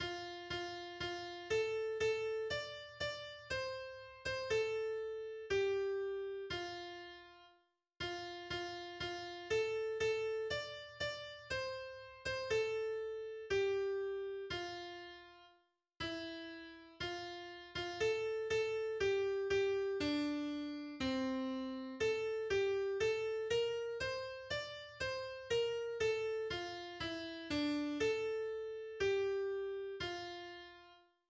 Liebeslied aus der 1. Hälfte des 15. Jahrhunderts